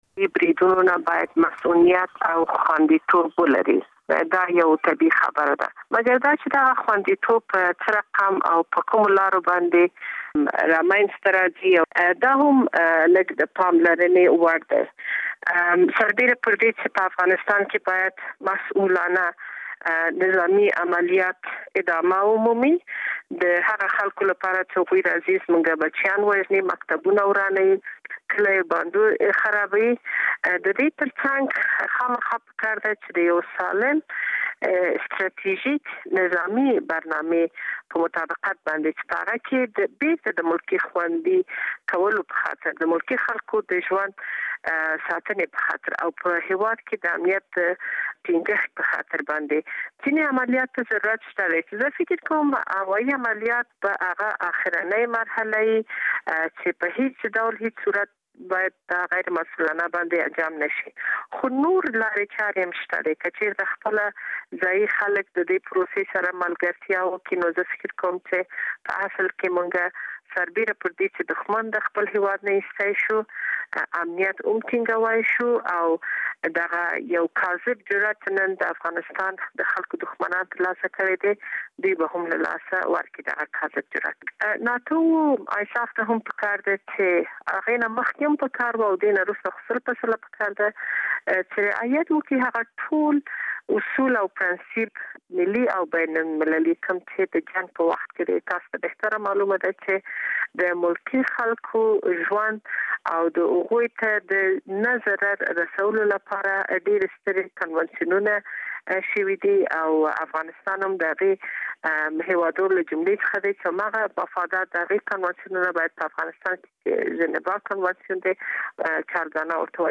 د جان الن د پرېکړې په اړه له شکریې بارکزۍ سره مرکه